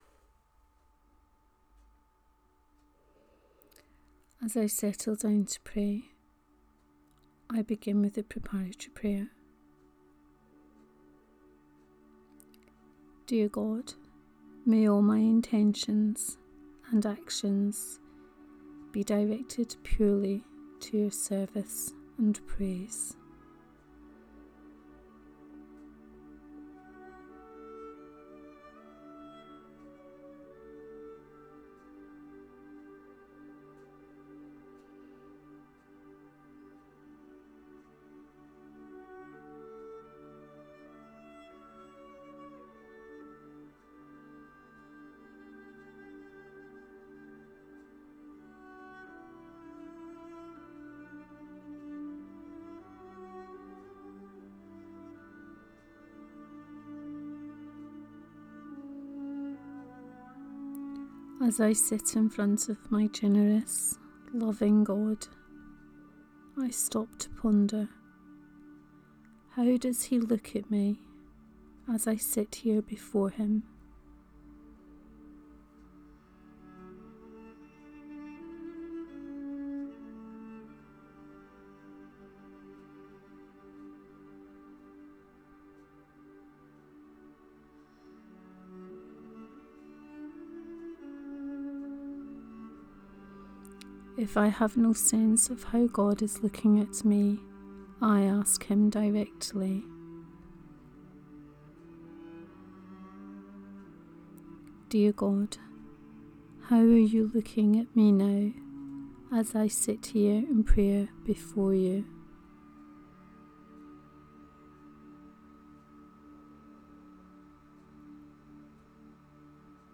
I have also used different background music for this prayer, as befits the grace of sorrow for this Holy Week.